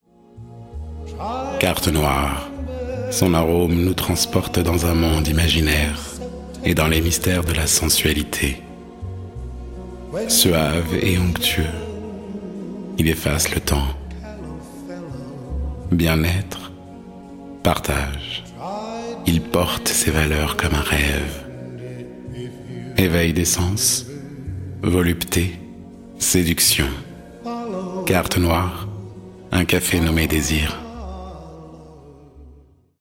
Kein Dialekt
Sprechproben: